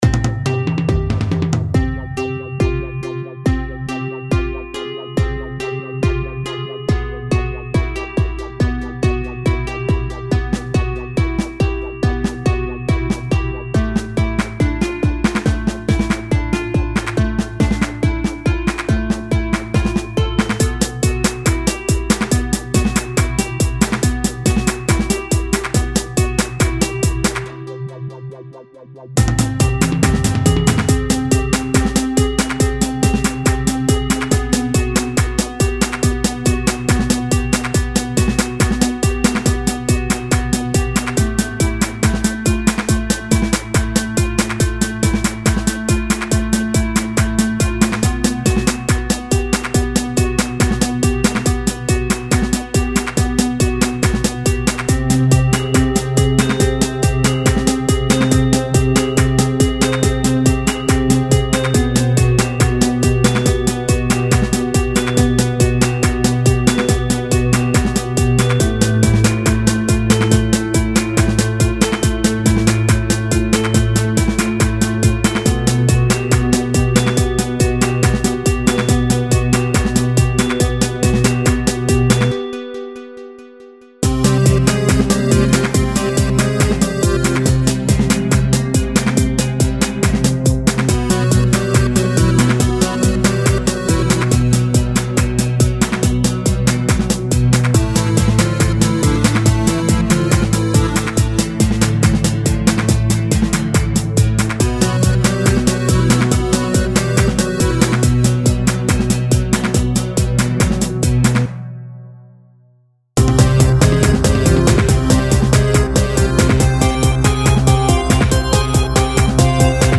An erie techno song
in Fruity loops.